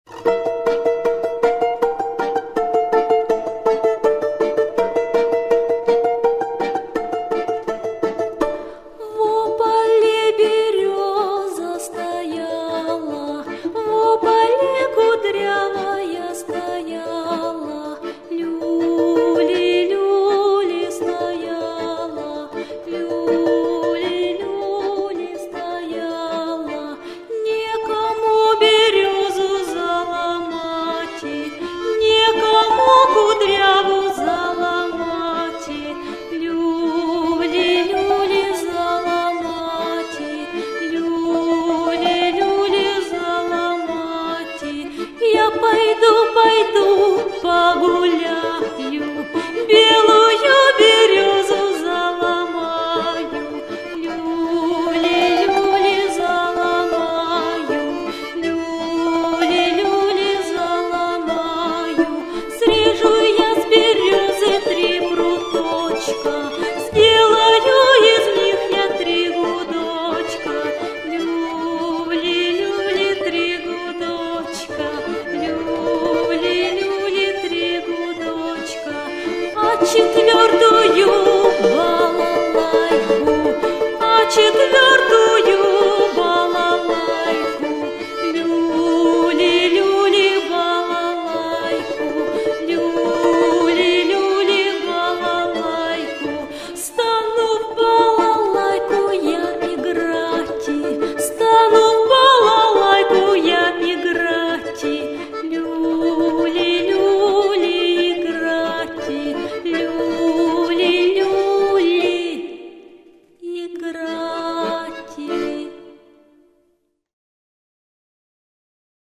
Слова и музыка народные.